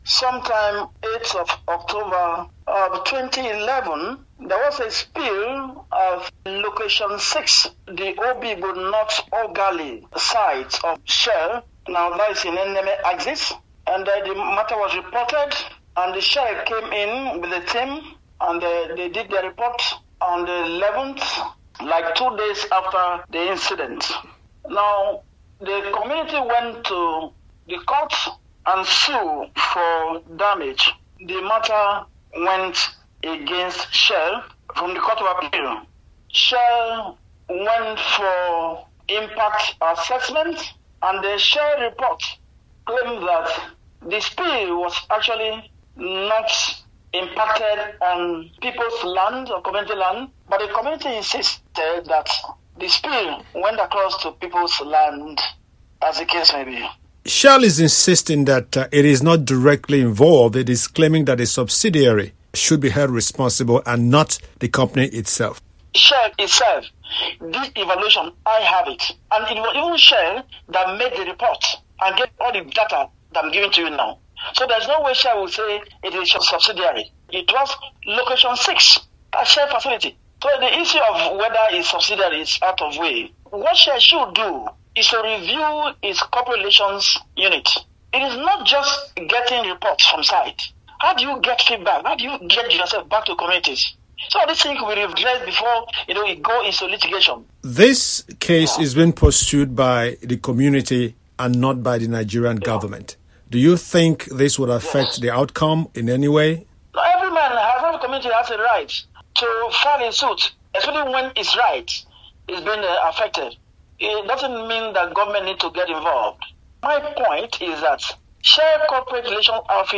A Nigerian analyst says the landmark court case in London against oil giant Shell and its Nigerian subsidiary for decades of alleged oil spills in the Niger Delta is an attempt by the affected communities to seek justice. Shell, however, says most spills were caused by illegal third-party interference, such as pipeline sabotage and theft, which is rife in the region.